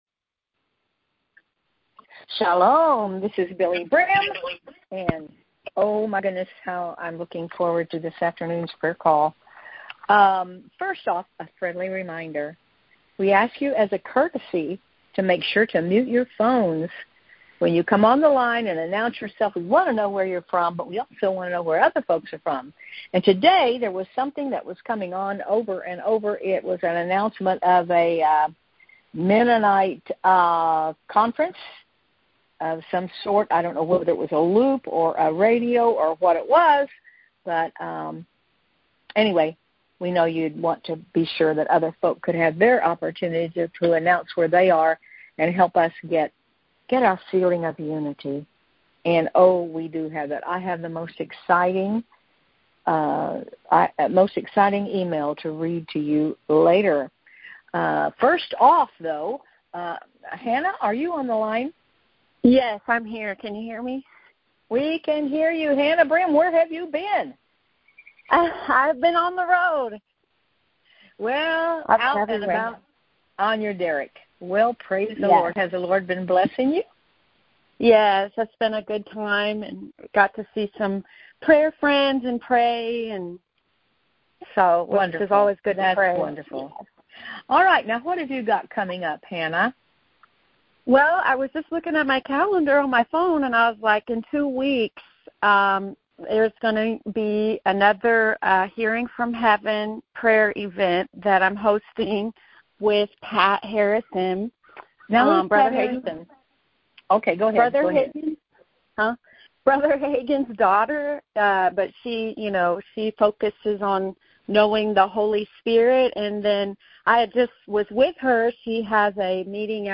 The audio was recorded via our BBM Phone Cast system.